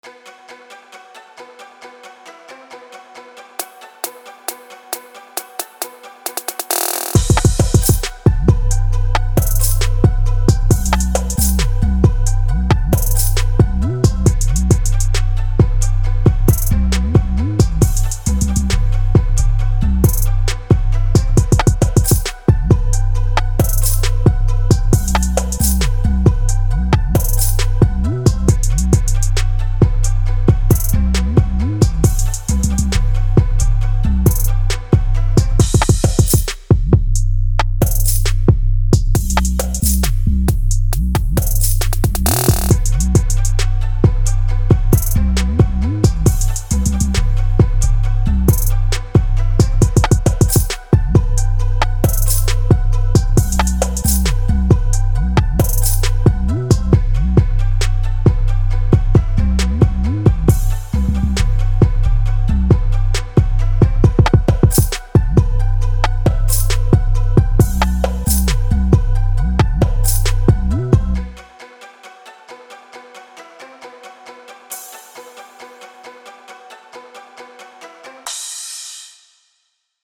Inspired by the freshest Trap producers’ output, this pack is a nice addition to any Trap or Hip Hop producer’s repertoire, all 100% Royalty-Free.
Venom-Drums-Full-Demo.mp3